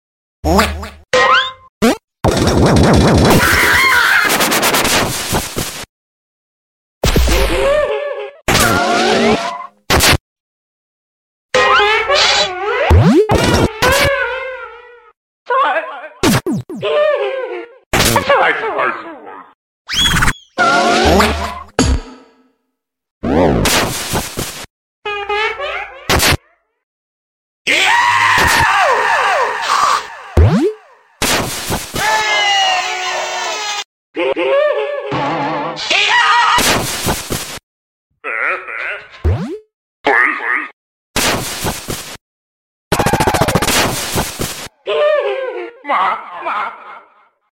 brawl stars with pizza Tower sound effects free download